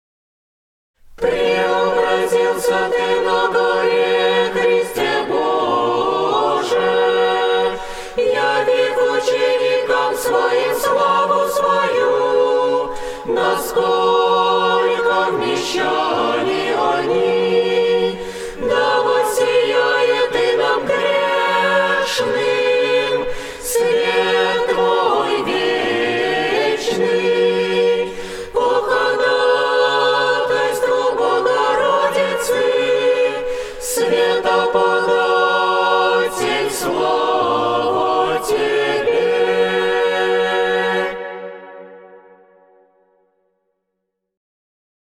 Хор Преображенского братства - Преображение Господне Глас 7.mp3